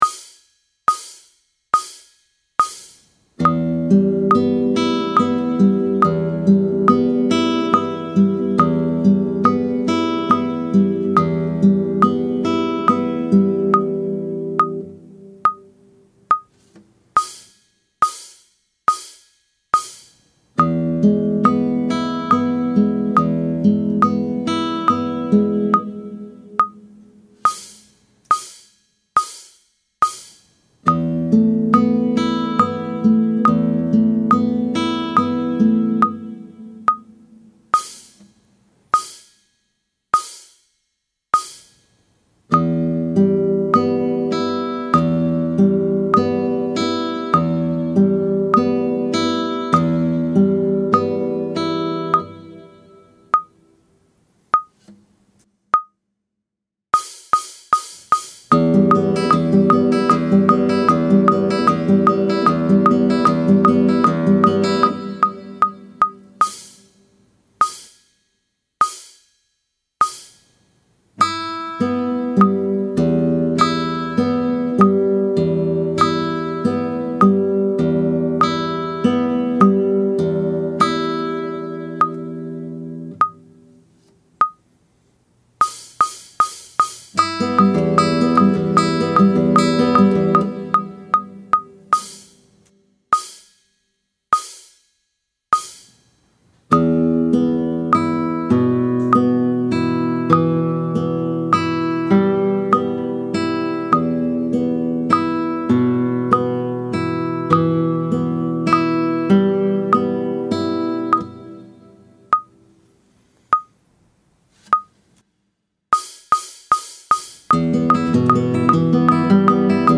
This video is a CLASSICAL guitar tutorial for nOOBS. Inside you'll learn about the differences between acoustic and classical guitars, planting technique, rest strokes and free strokes.